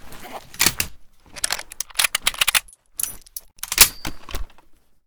sks_reload.ogg